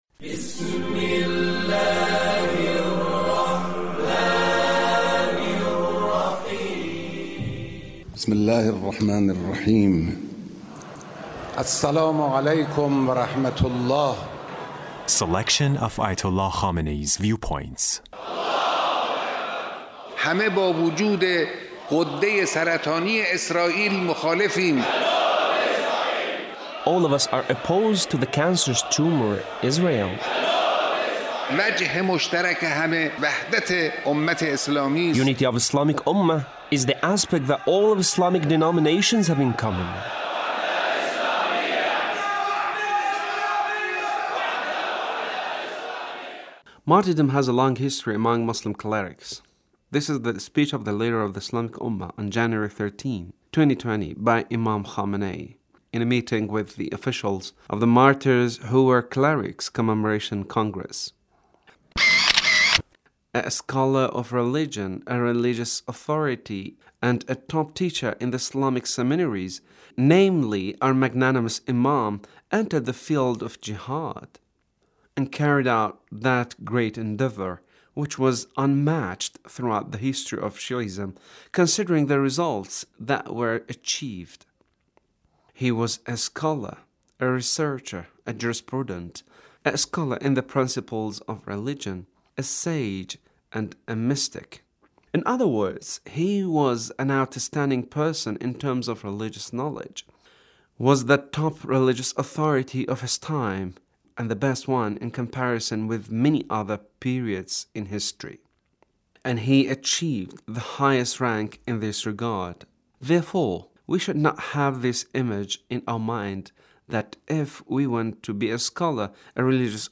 The Leader's speech on Quds Day